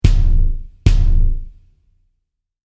Bassdrum-Tuning: Hardrock-Sound
Darunter ist ein resonanter Sound mit deutlichem, "knackigen" Attack sowie tiefem, relativ langen Sustain mit nicht allzu hellen Obertönen zu verstehen. Als Frontfell wird ein einlagiges, mit eingearbeitetem Dämpfungsring und Luftausgleichsloch ausgestattetes Fell eingesetzt.
Als Schlagfell wird ein durchsichtiges, doppellagiges Fell verwendet.
Das Resonanzfell wird im Verhältnis zum so tief als möglich zu stimmenden Schlagfell (der Rand wirft gerade keine Falten mehr!) um mindestens drei  Ganztöne höher gestimmt.